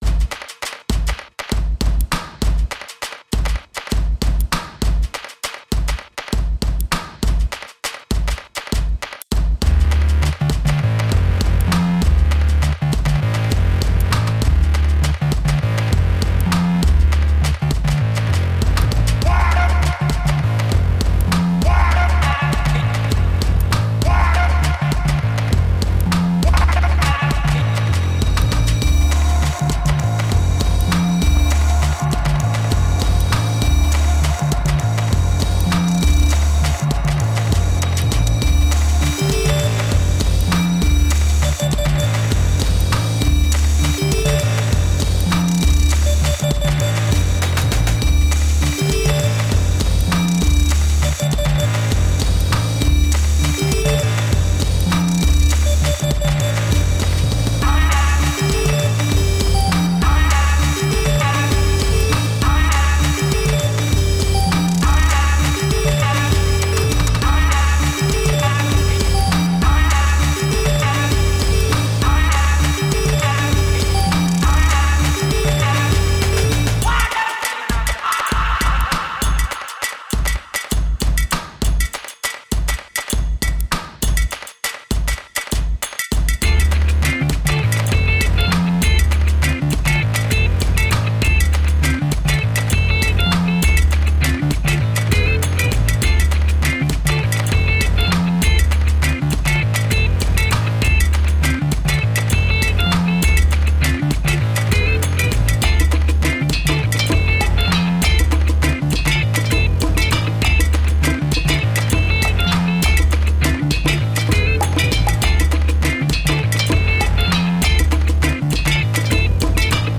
Guitar amps in warehouse raves.